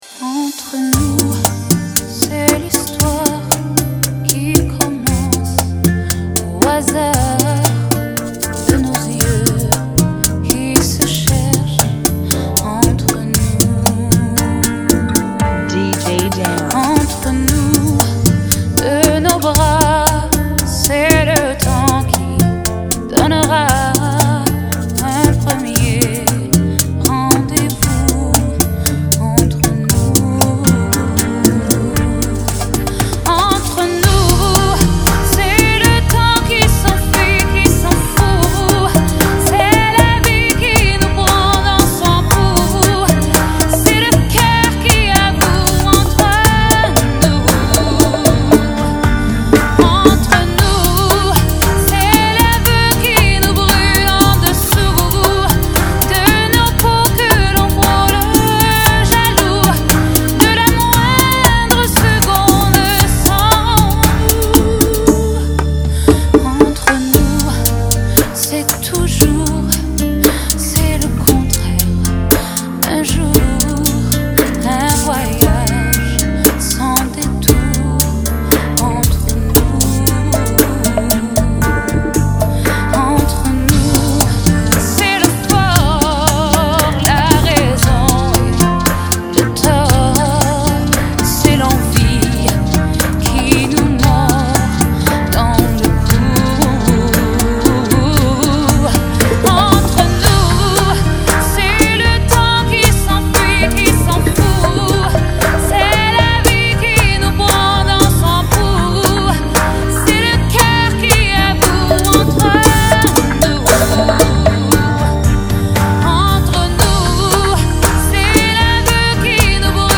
116 BPM
Genre: Bachata Remix